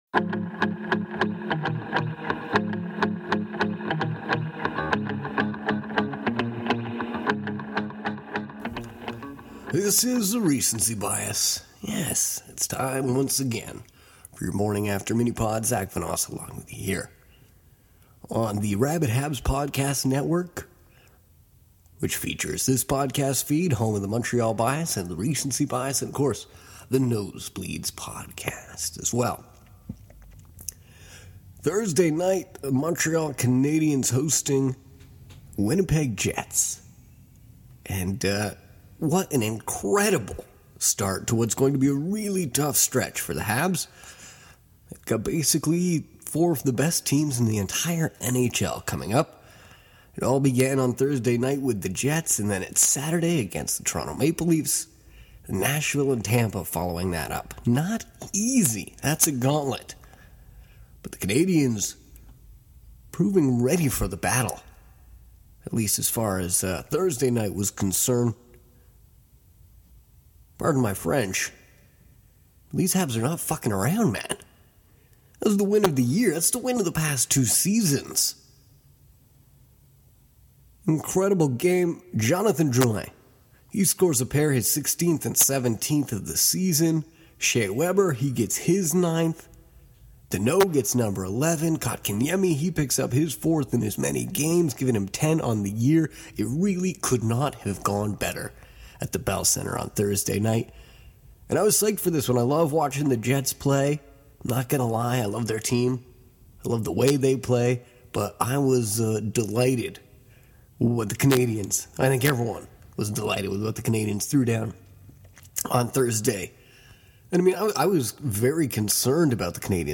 Appreciate your thoughts, but the reverb on the audio is hard to ignore